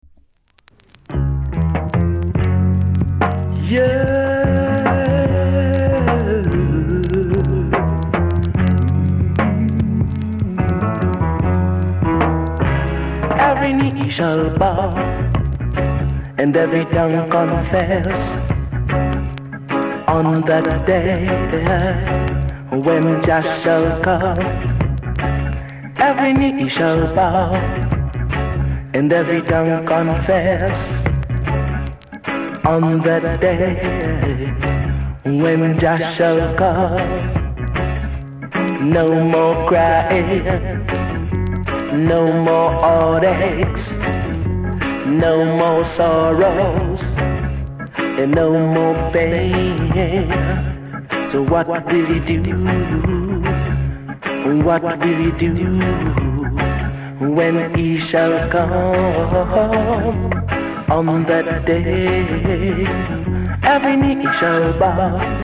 ※出だしでプチプチします。チリ、パチノイズが単発であります。